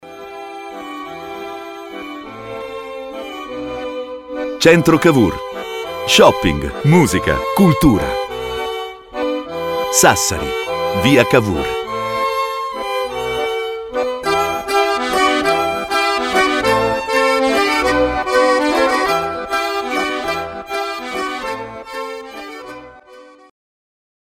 Spot Istituzionale